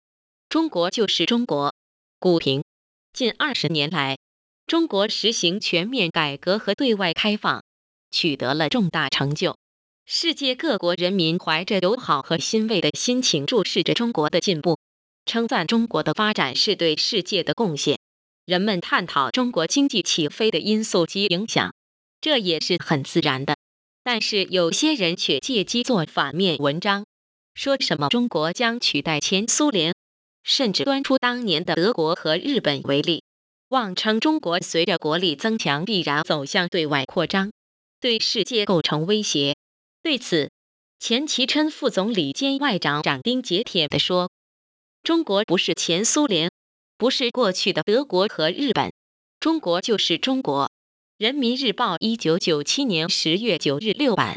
These links point to the three synthetic wave files, of which the first one was generated by our KBCT2.0 Cantonese TTS engine, the next one was generated by our KBCE2.0 Chinese-English Mixed-lingual TTS engine, and the last one was generated by our KD2000 Chinese TTS engine.